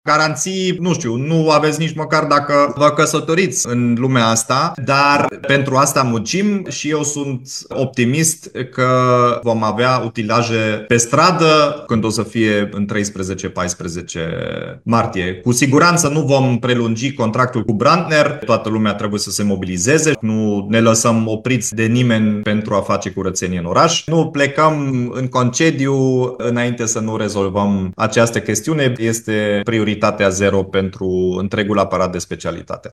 Într-un răspuns pentru consilierul Cosmin Tabără, care a cerut garanții ca societatea să opereze imediat ce expiră acordul cu actualul prestator, primarul Dominic Fritz a precizat că utilajele vor fi pe străzi încă din prima zi.